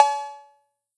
python-drum-sequencer/808/003.wav at 588c864cf7e6a28338cc942b2bb14b6476ef6e97